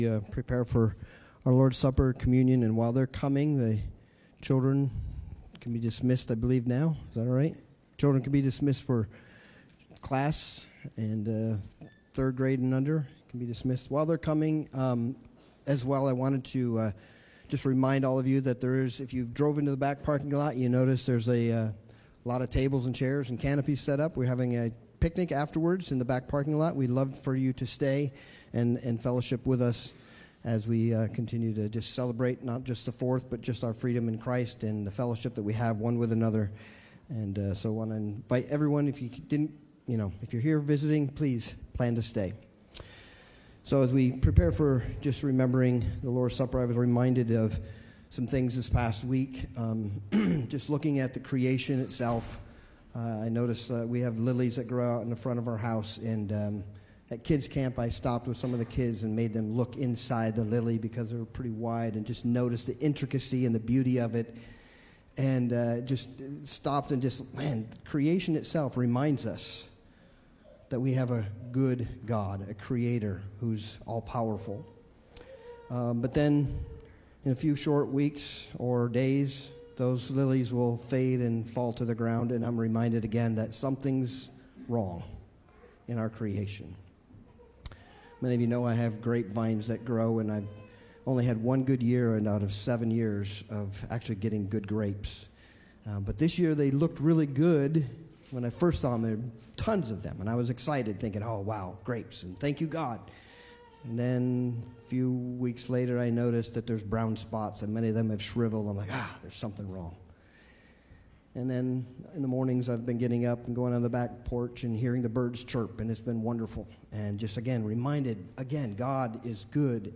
Sermons | Calvary Baptist Church
Single sermons that are not part of a series.